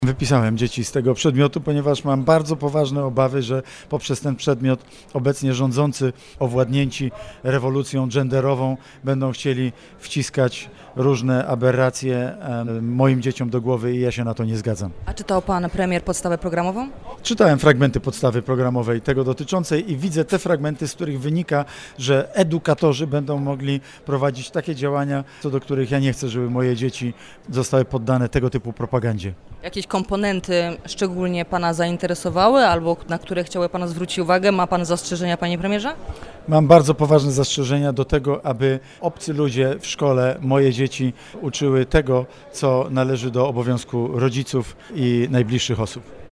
Nie chcę, aby obcy ludzie uczyli moje dzieci tego, co należy do obowiązków rodziców – mówił w „Porannym Gościu: Mateusz Morawiecki.